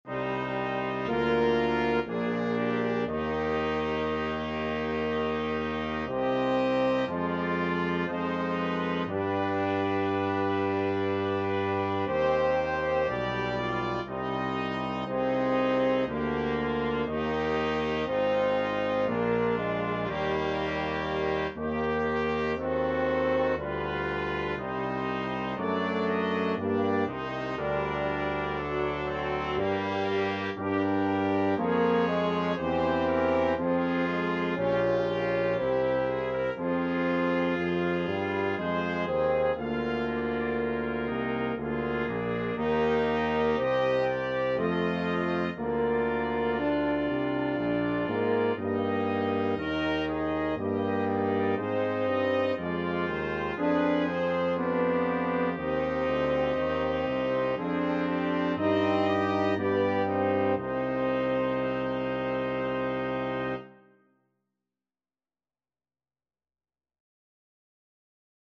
Trumpet 1Trumpet 2French HornTromboneTuba
3/4 (View more 3/4 Music)
Lento
Classical (View more Classical Brass Quintet Music)